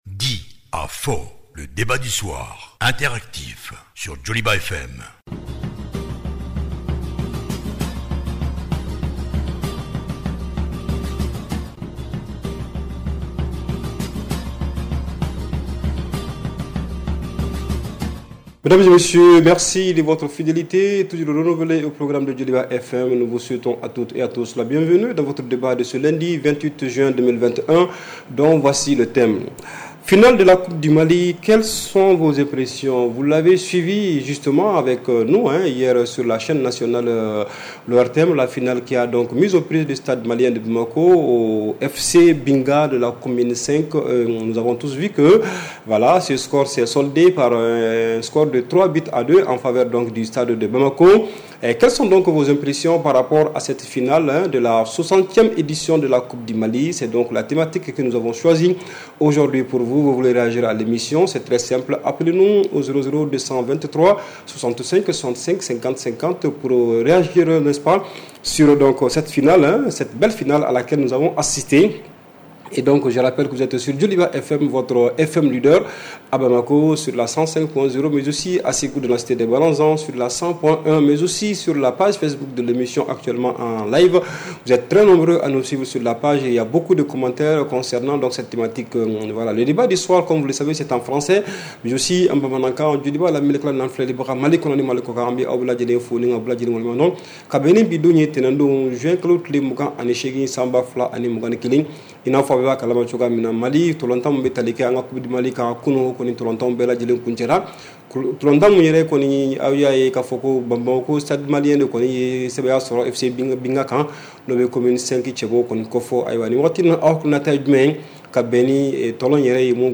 REPLAY 28/06 – « DIS ! » Le Débat Interactif du Soir